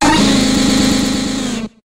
dudunsparce_ambient.ogg